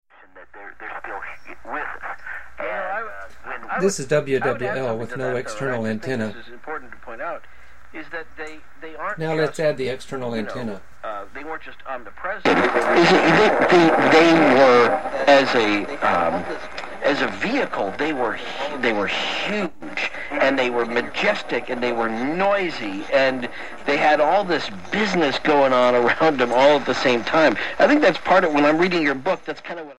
Using ordinary stereo headphones, at times the radio was
so loud I had to disconnect the outdoor antenna .